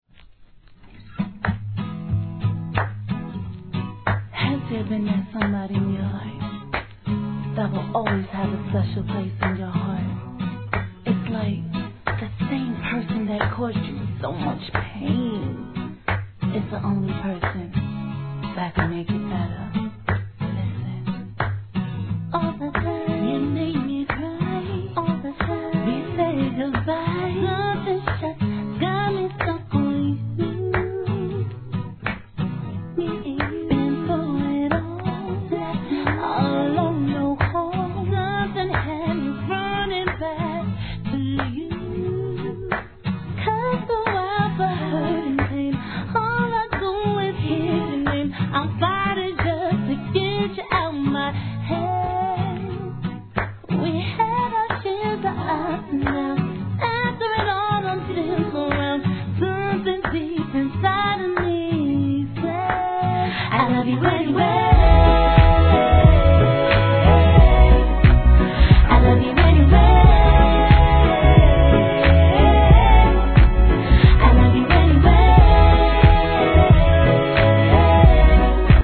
REGGAE
2008年、アコースティックと手拍子からメロディーが乗ってくる展開でLOVELYに歌い上げた逸品!